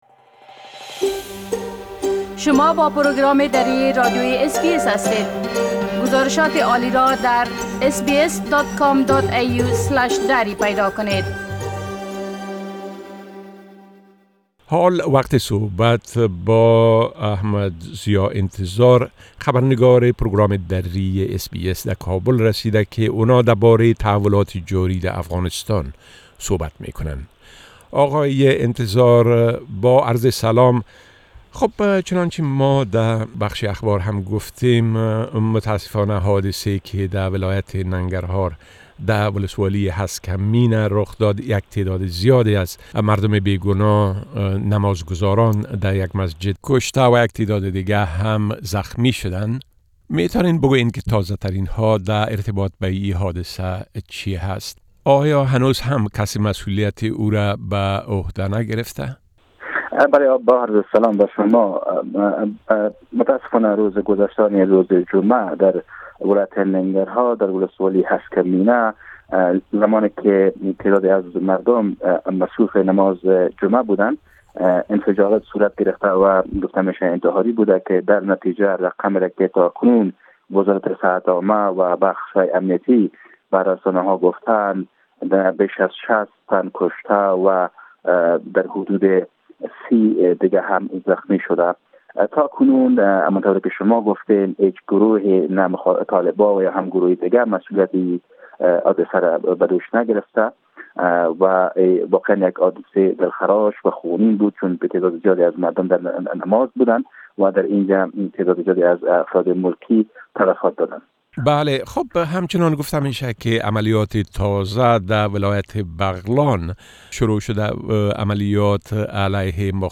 A report from our correspondent in Afghanistan which can be heard here in Dari language